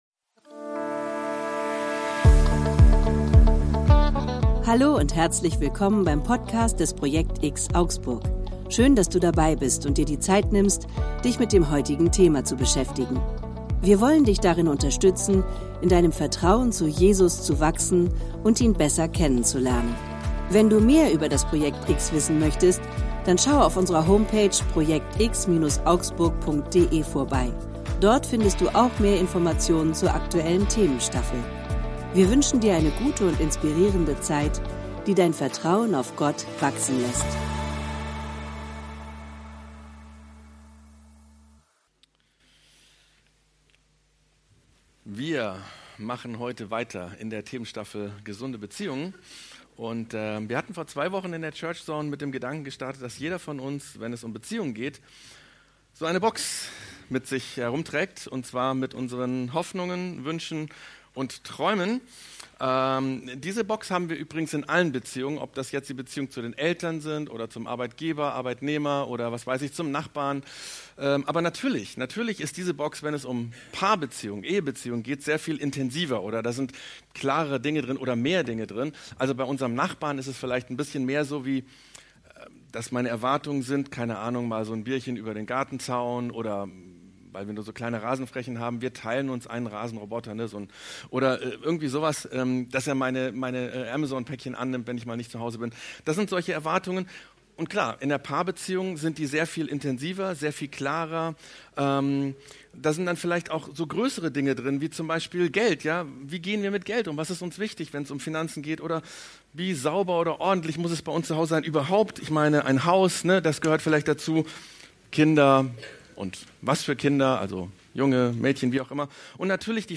Predigten im Rahmen der church zone vom projekt_X in Augsburg